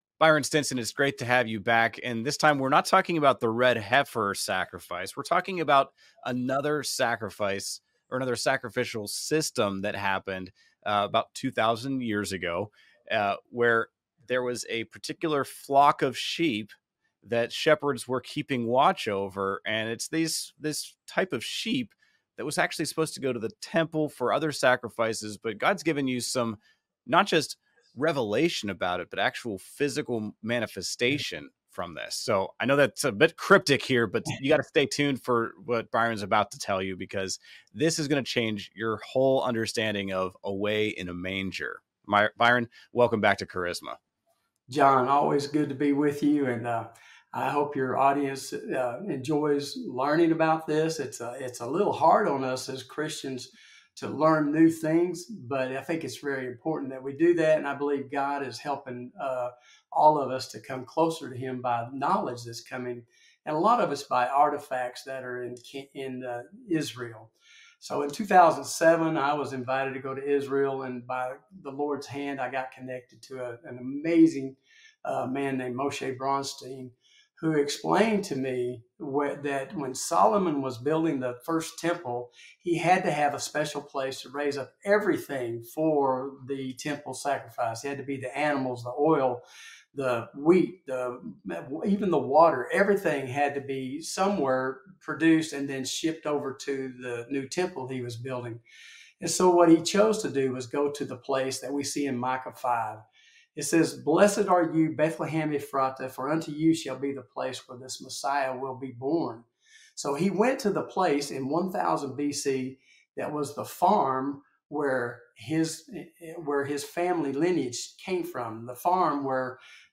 News stories and interviews well told from a Christian perspective.